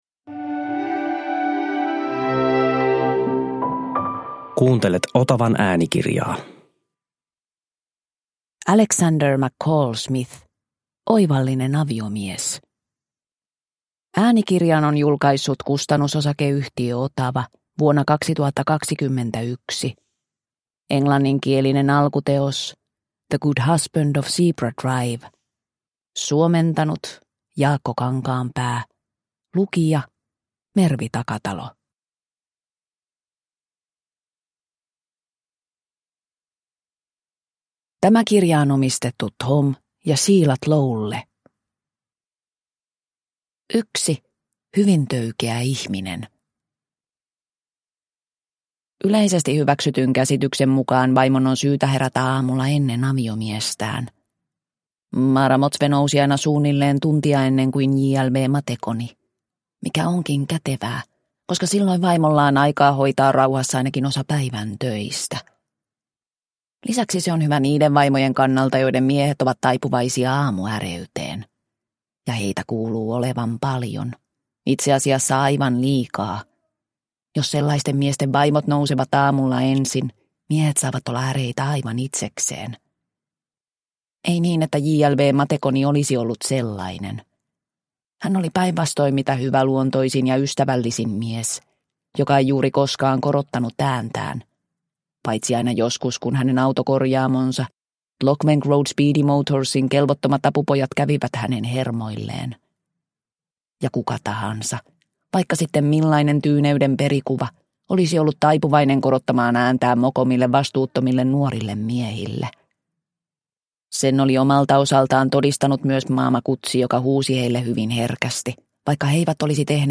Oivallinen aviomies – Ljudbok – Laddas ner